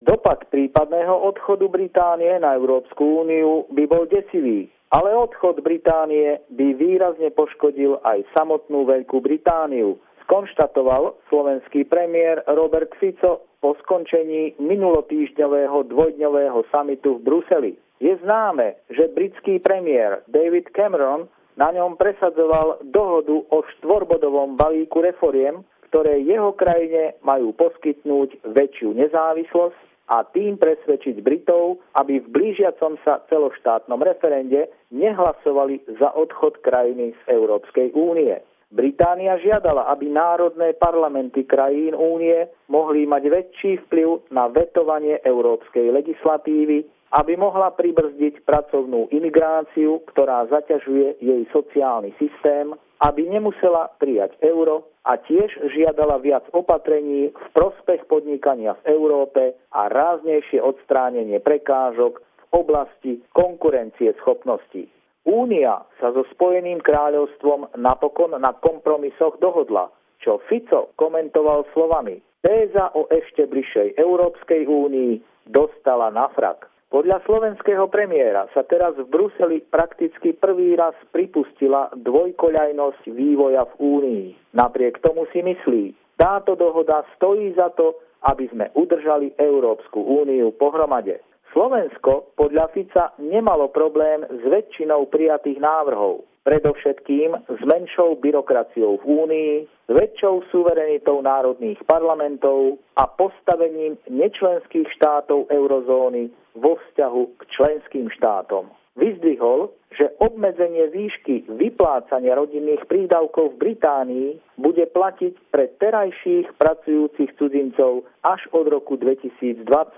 Pravidelný telefonát týždňa od nášho kolegu